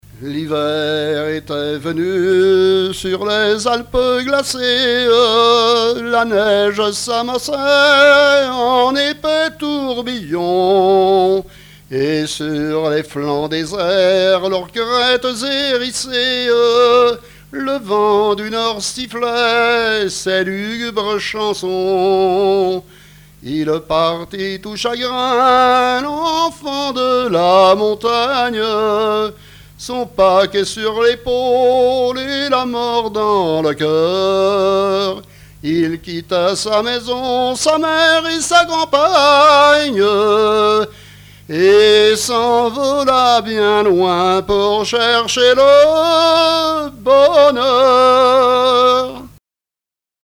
Genre strophique
témoignages et trois chansons
Pièce musicale inédite